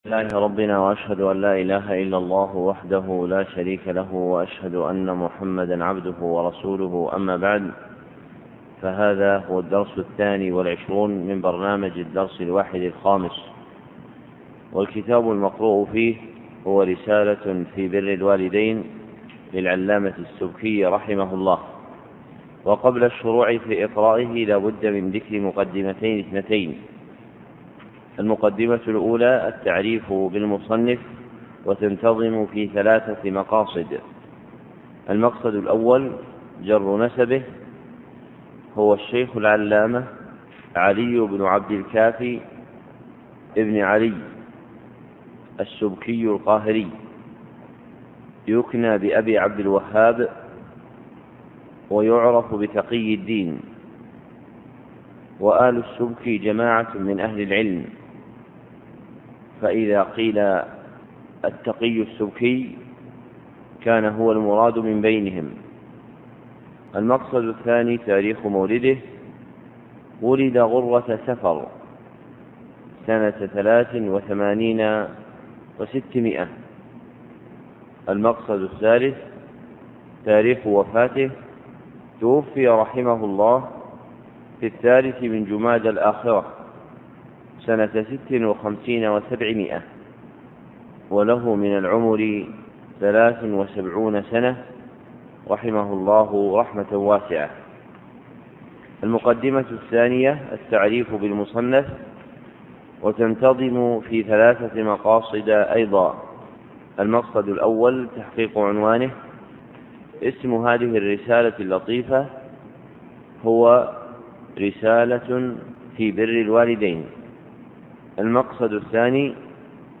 محاضرة صوتية نافعة